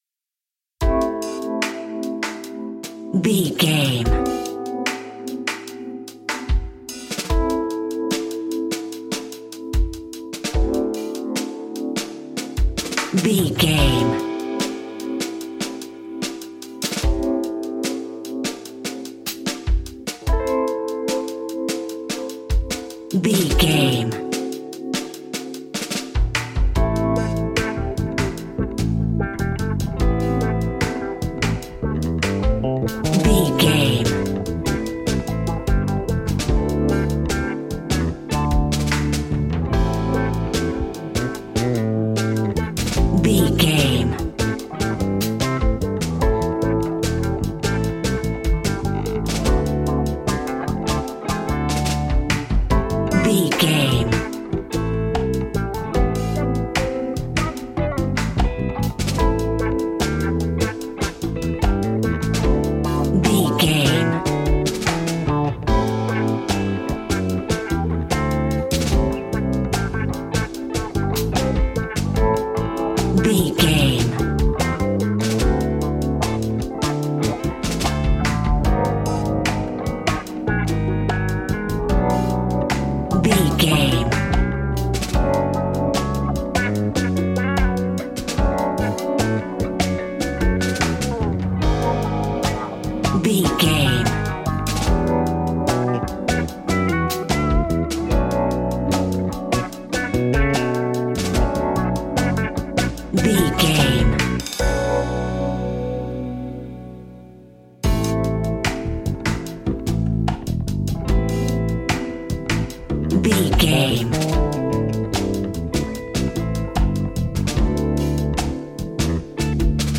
Epic / Action
Fast paced
In-crescendo
Uplifting
Ionian/Major
hip hop
instrumentals